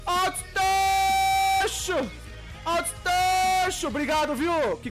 distortion.mp3